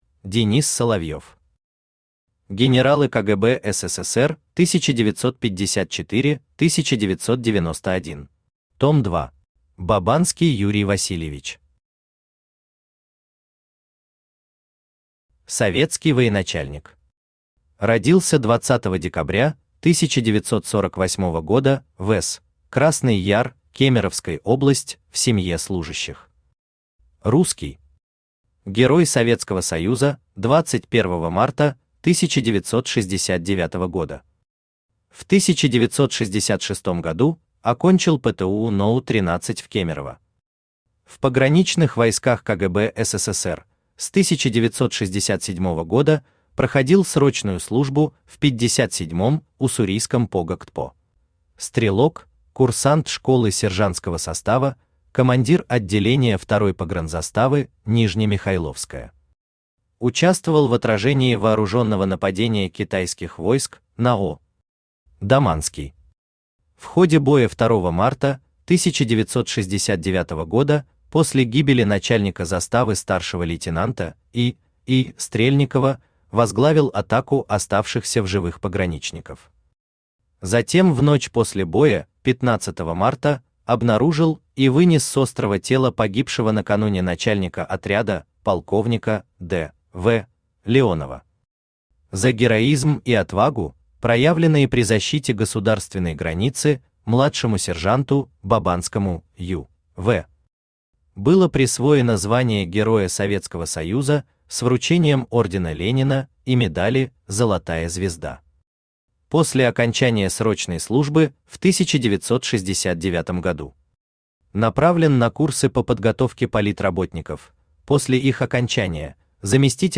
Aудиокнига Генералы КГБ СССР 1954-1991.Том 2 Автор Денис Соловьев Читает аудиокнигу Авточтец ЛитРес.